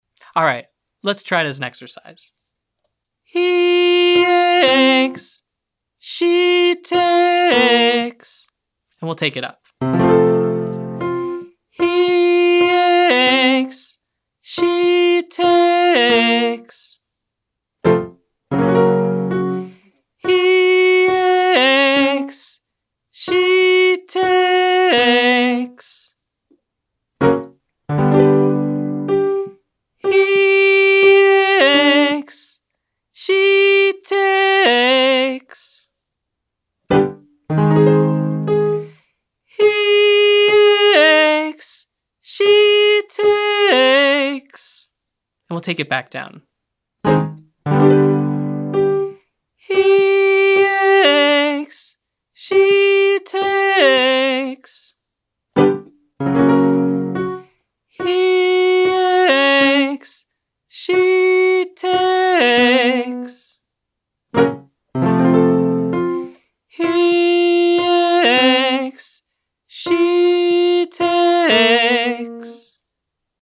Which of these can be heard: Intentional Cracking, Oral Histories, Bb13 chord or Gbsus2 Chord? Intentional Cracking